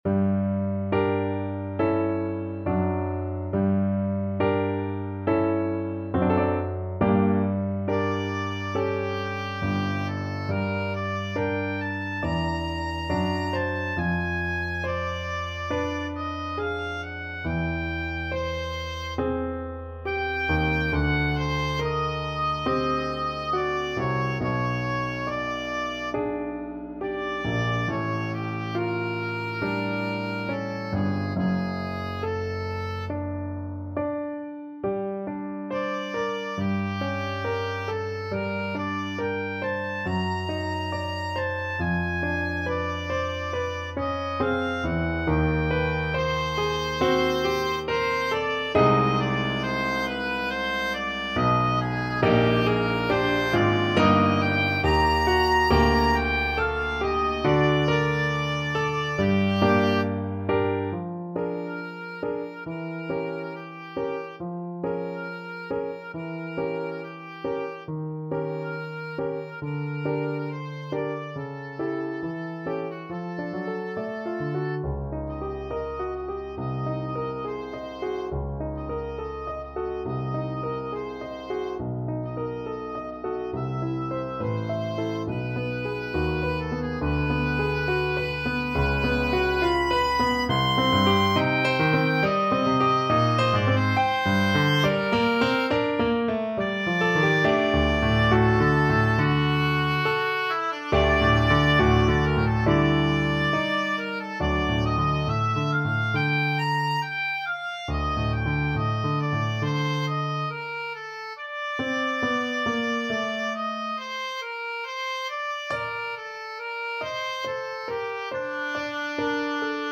Oboe
Bb major (Sounding Pitch) (View more Bb major Music for Oboe )
~ = 69 Andante con duolo
Classical (View more Classical Oboe Music)